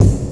Kick_23_b.wav